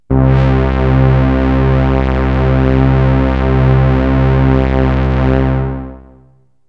AMBIENT ATMOSPHERES-4 0009.wav